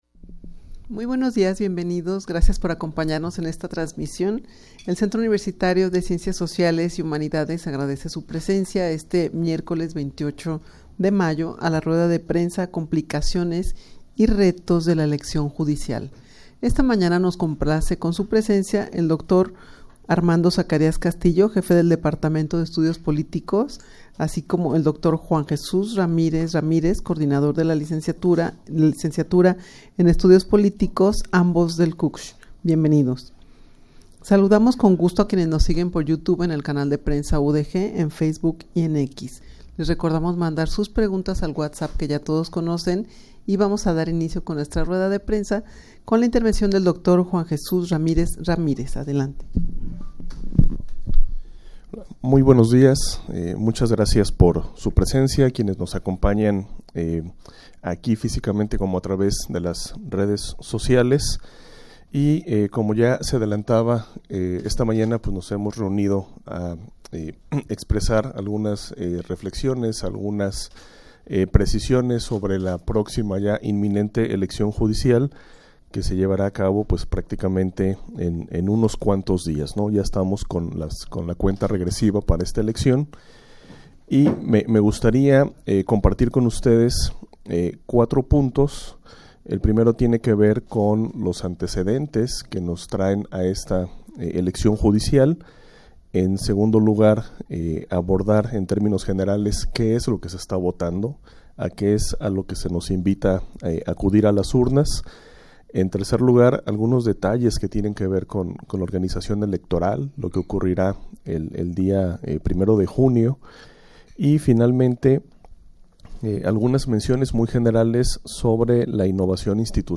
Audio de la Rueda de Prensa
rueda-de-prensa-complicaciones-y-retos-de-la-eleccion-judicial.mp3